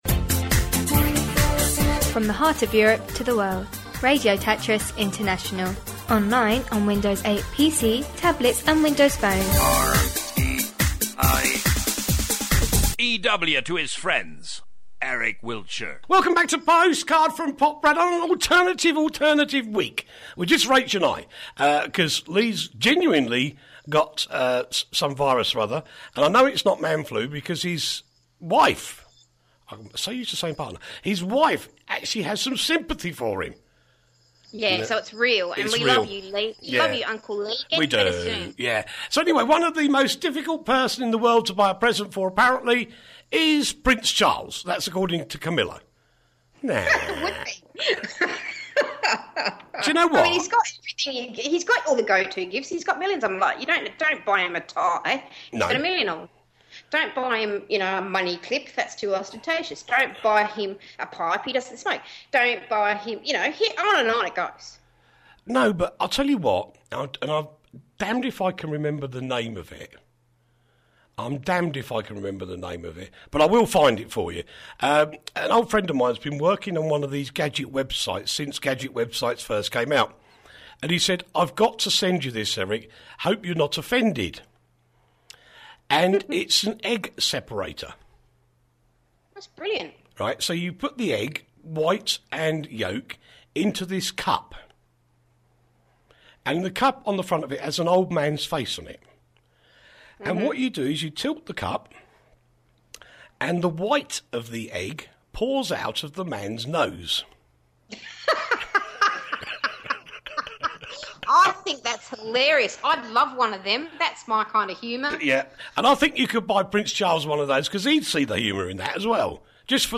the alternative news show